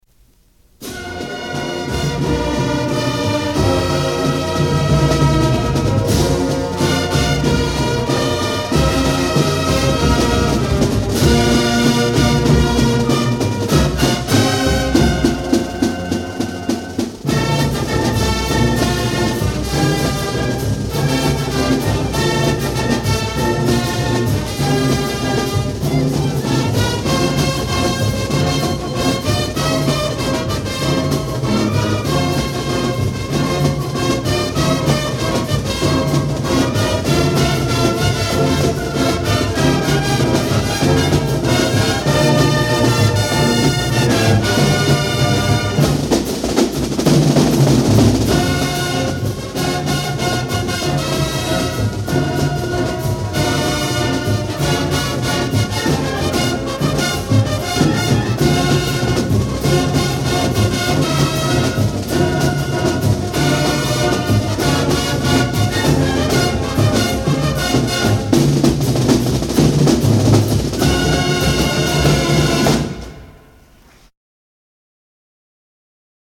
1975 Marching Falcons In Concert Program